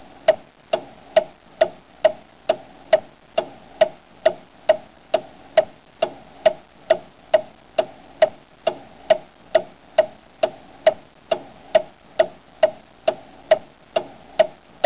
Clocktick
ClockTick.wav